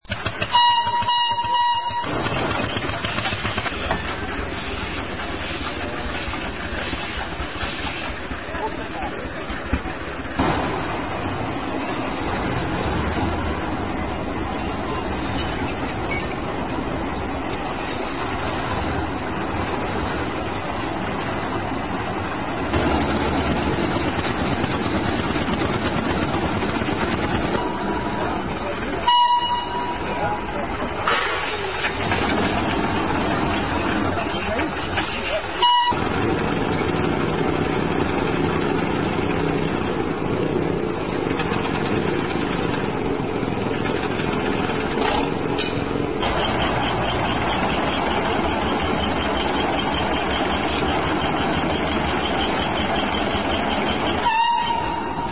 GL127-1 Steam Donkey sound